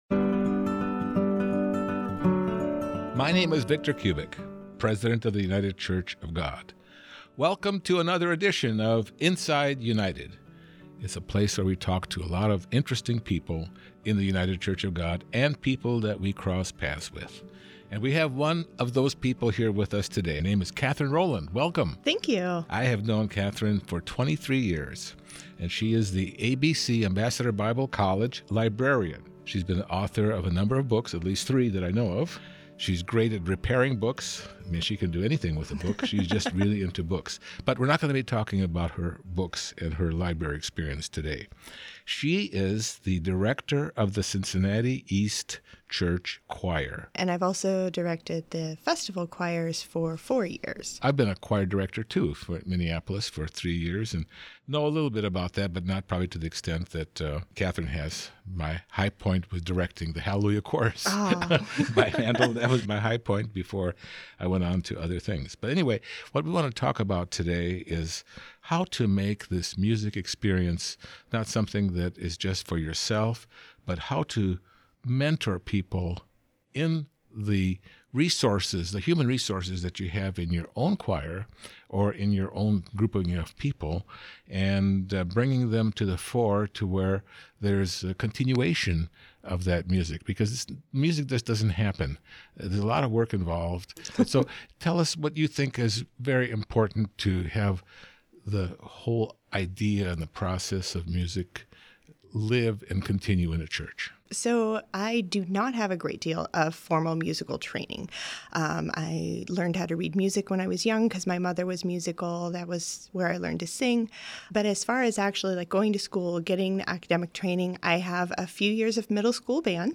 We revisit an interview